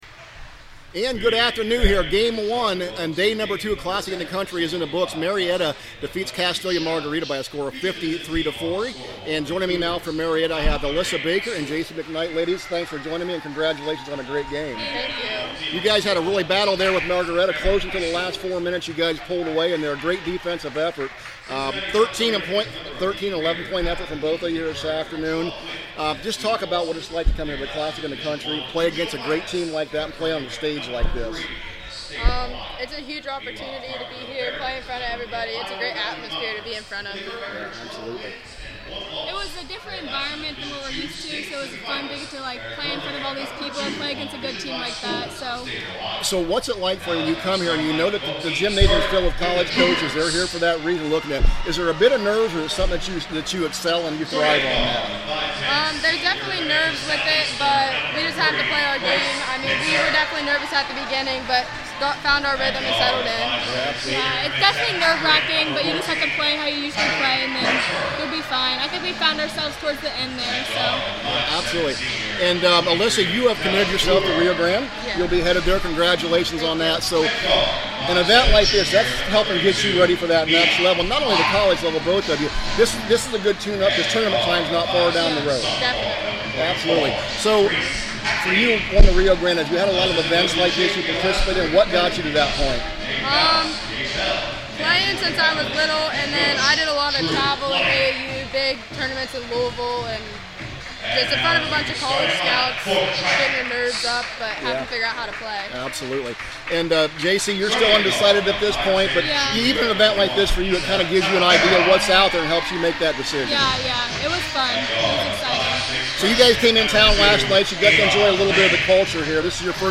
2025 Classic In the Country – Marietta Player Interviews